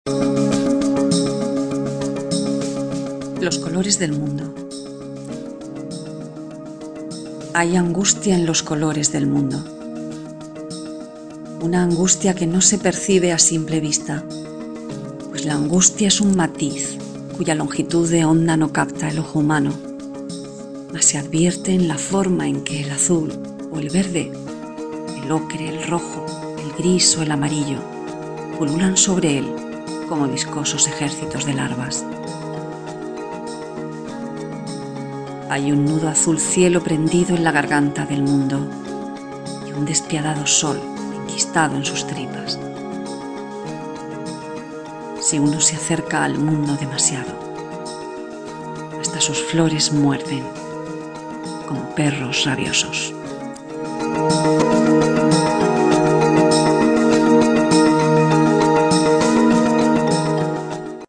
Inicio Multimedia Audiopoemas Los colores del mundo.
VIII Certamen «Poemas sin Rostro» 2013